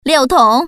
Index of /mahjong_paohuzi_Common_test/update/1658/res/sfx/putonghua/woman/